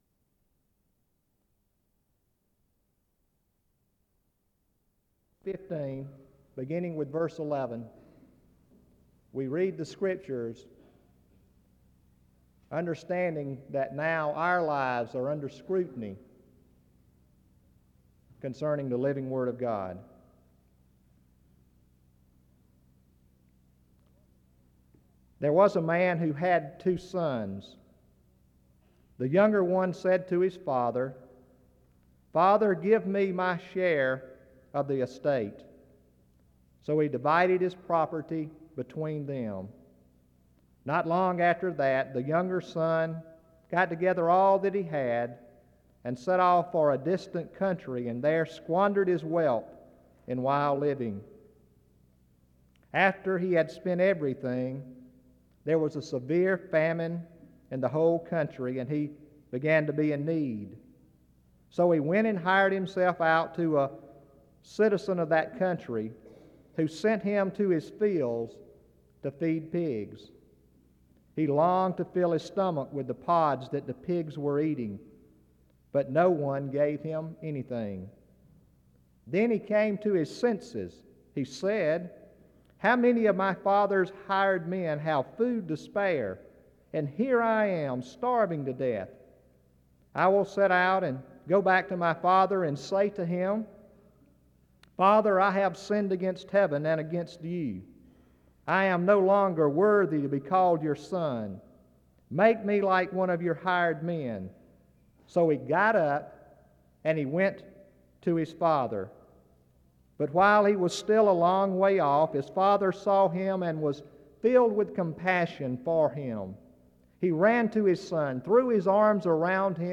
The service starts with the reading of the parable of the Prodigal Son from 0:00-2:41.
Music plays from 2:47-2:56.